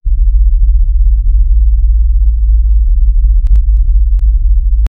The sound of a massive, heavy starship. A deep, vibrating sub-bass hum that shakes the hull.
the-sound-of-a-massive-7bq556rh.wav